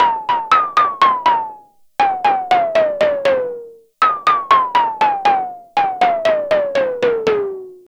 Astro 4 Synth.wav